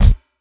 59 BD 1   -L.wav